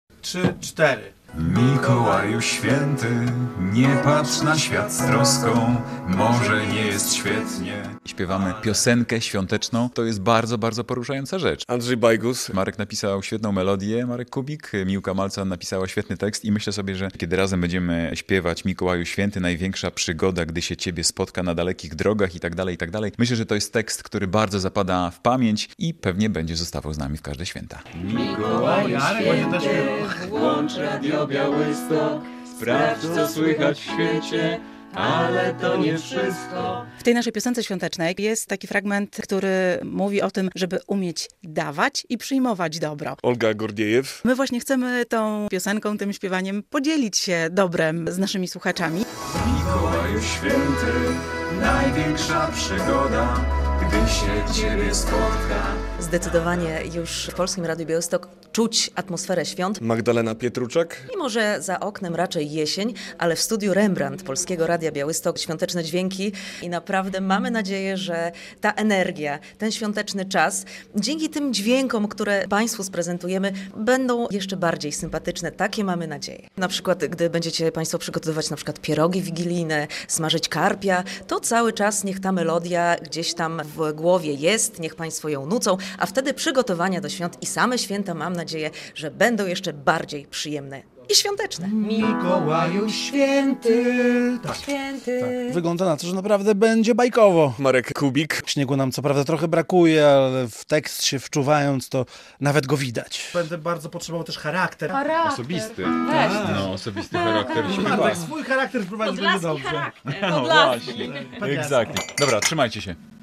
Premiera już niebawem, a we wtorek (3.12) na korytarzach i w Studiu Rembrandt Polskiego Radia Białystok rozbrzmiewały dźwięki świątecznej piosenki.
W utworze będzie można usłyszeć głosy kilkunastu pracowników, którzy we wtorek nagrywali ten świąteczny utwór.